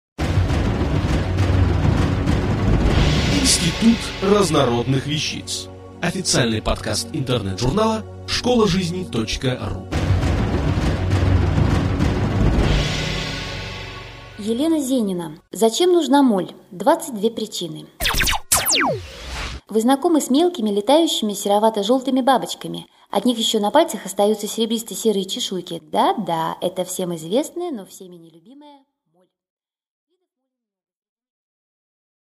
Аудиокнига Зачем нужна моль? 22 причины | Библиотека аудиокниг